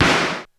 Kick 02.wav